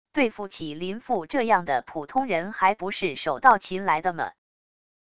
对付起林父这样的普通人还不是手到擒来的么wav音频生成系统WAV Audio Player